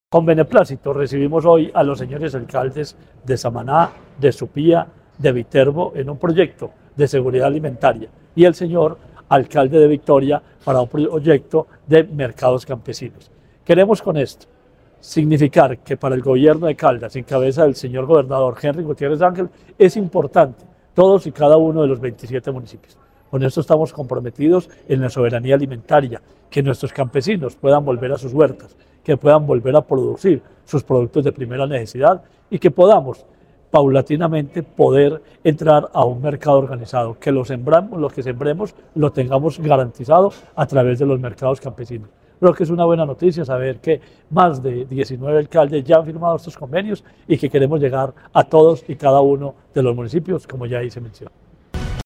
Marino Murillo Franco, secretario de Agricultura y Desarrollo Rural de Caldas.